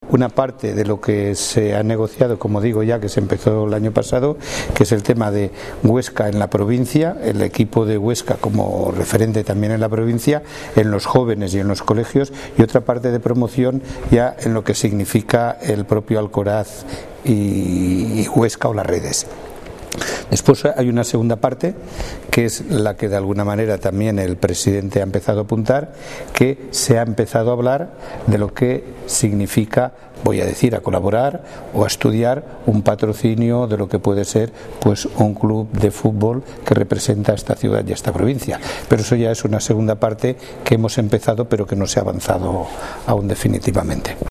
Audio del Presidente de la Diputación de Huesca, Miguel Gracia: